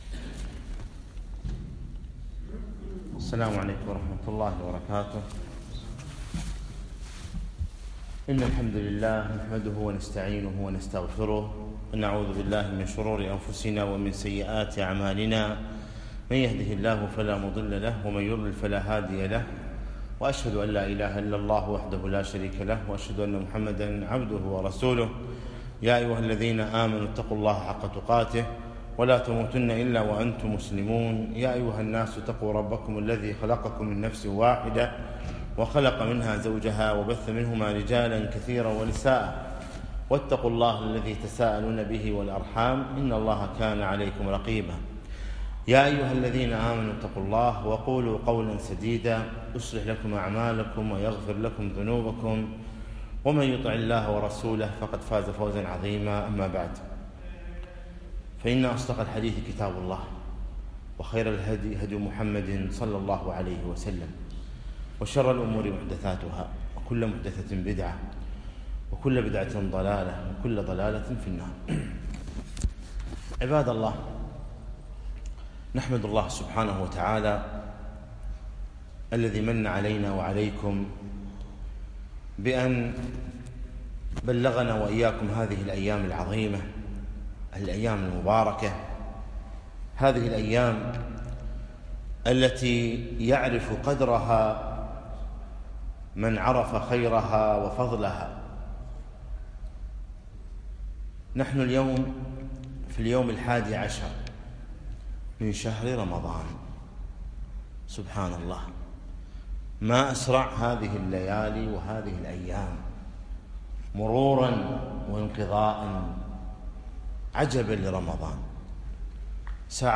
الثلاثاء 11 رمضان 1438 الموافق 6 6 2017 بمسجد الحسن بن علي الدوحة